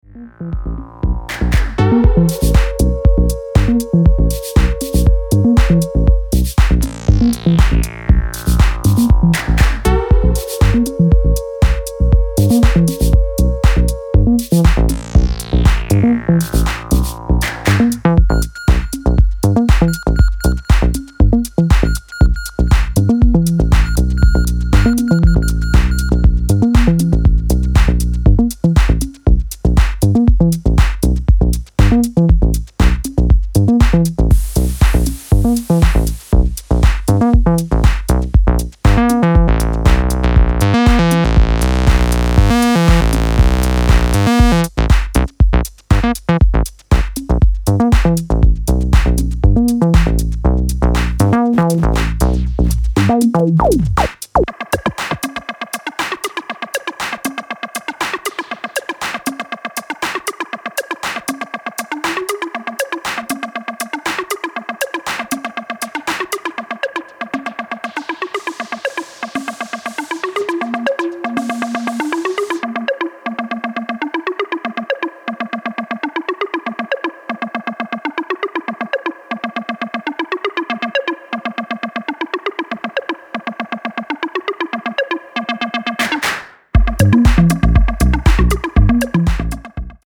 Styl: Electro, House, Techno